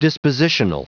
Prononciation du mot dispositional en anglais (fichier audio)
Prononciation du mot : dispositional